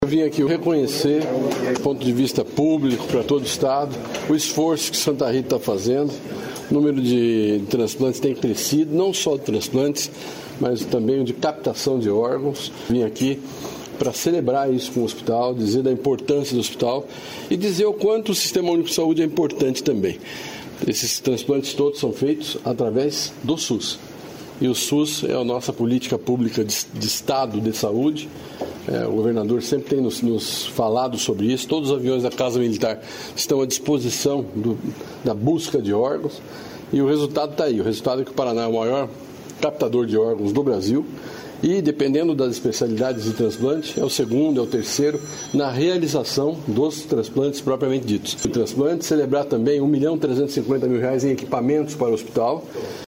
Sonora do secretário Estadual da Saúde, Beto Preto, sobre o Hospital Santa Rita